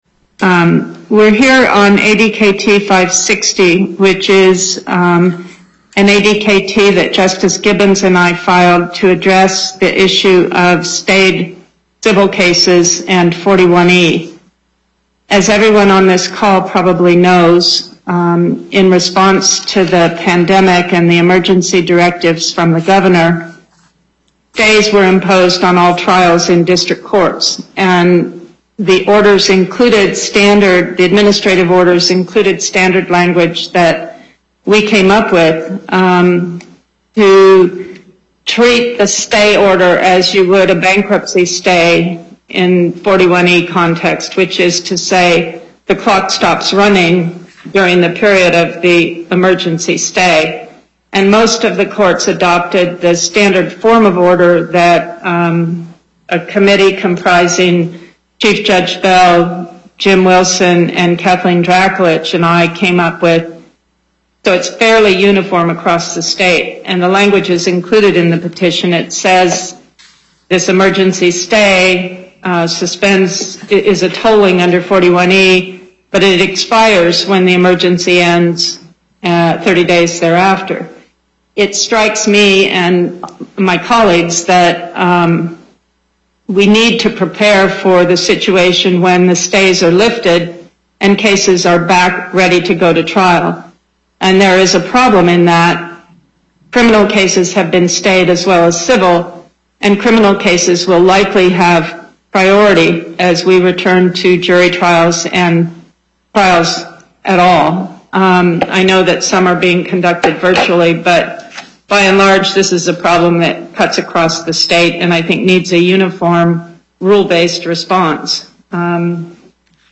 Location: Carson City and Las Vegas Before the En Banc, Chief Justice Pickering Presiding Appearances